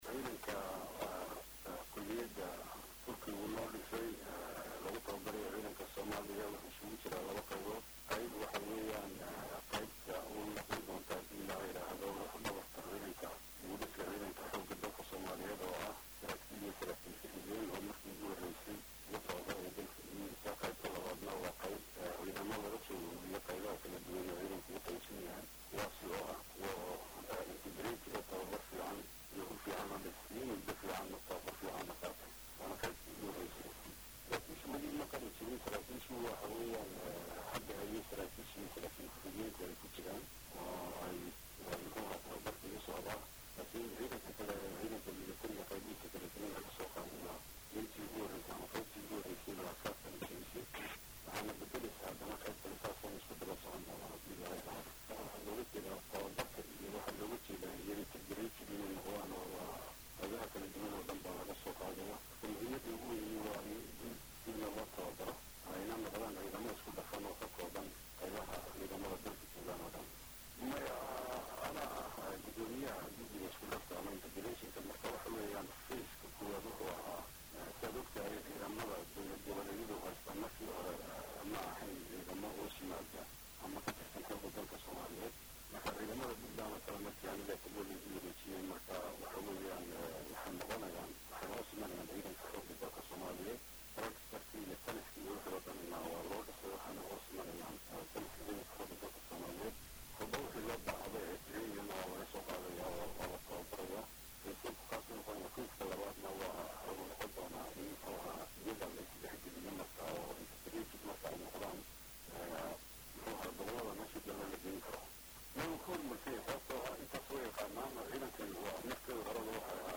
Wasiir Dowlaha Wasaaradda Gaashaandhiga Xukumadda Federaalka Soomaaliya mudane Maxamed Cali Xagaa oo la hadlay Laanta Afka Soomaaliga ee VOA oo bah wadaag la ah Warbaahinta Qaranka ayaa sheegay in uu meel wanaasan marayo Dhismaha iyo qalabaynta Ciidamada Xoogga Soomaaliya.